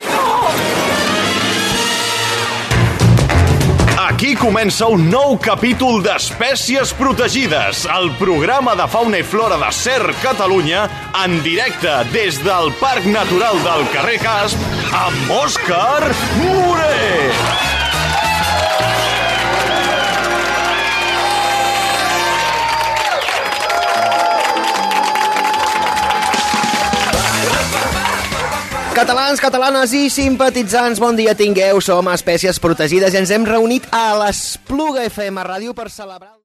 Careta del programa i salutació inicial
Entreteniment